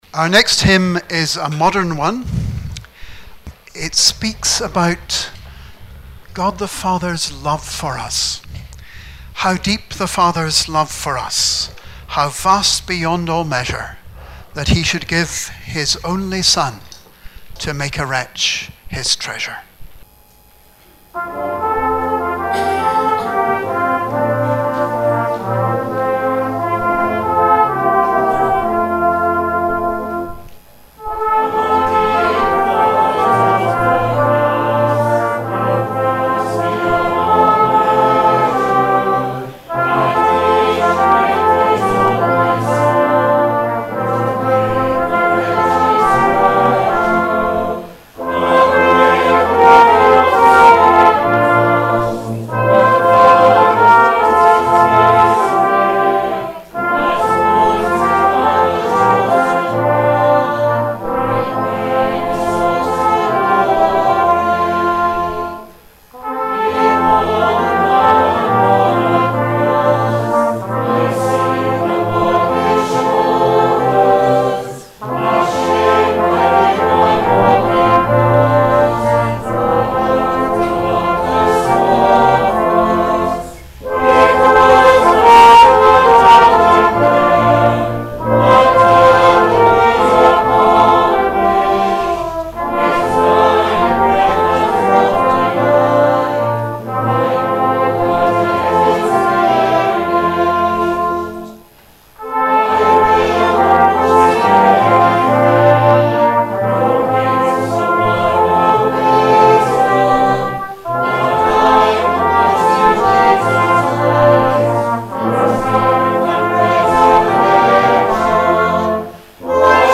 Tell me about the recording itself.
Remembrance Sunday - 10 November 2019